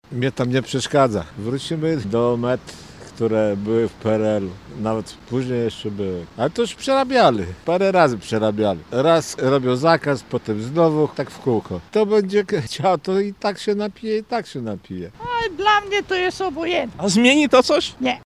CZYTAJ: Polskie miasta wprowadzają nocną prohibicję Mieszkańcy pytani w sobotę przez reportera Radia Lublin, co sądzą o zakazie, mają sceptyczne zdanie, co do jego sensu i skuteczności: – Wrócimy do „met”, które były w PRL-u. Kto będzie chciał się napić, to i tak się napije.